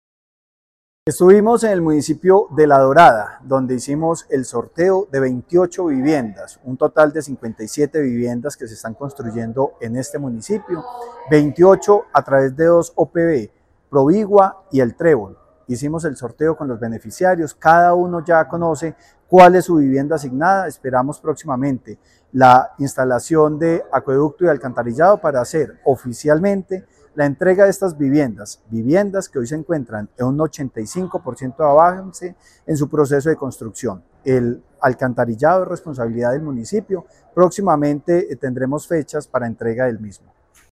Francisco Javier Vélez Quiroga, secretario de Vivienda de Caldas.